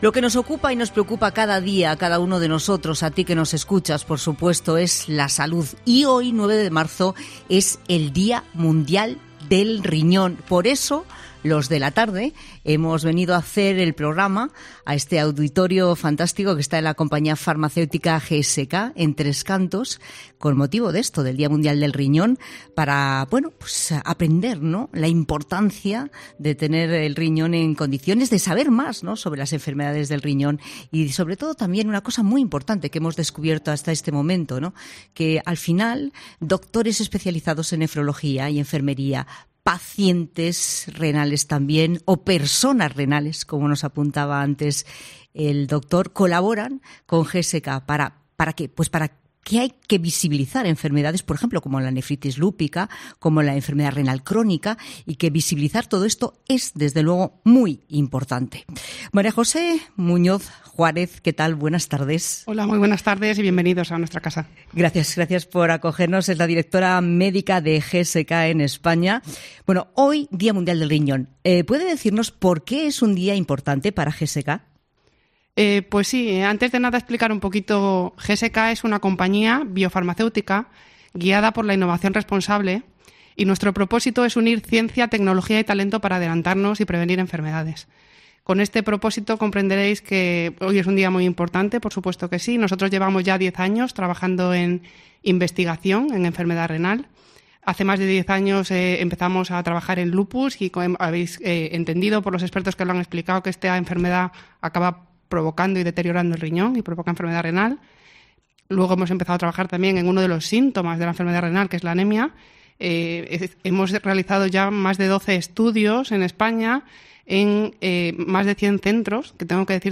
Este 9 de marzo es el Día Mundial del Riñón, por eso La Tarde se ha acercado hasta el auditorio de la compañía GSK en Tres Cantos, para aprender la importancia de cuidar la salud renal, que tanto médicos como asociaciones de pacientes trabajan para visibilizar enfermedades como la enfermedad renal crónica y una de sus complicaciones, la anemia renal, o la nefritis lúpica.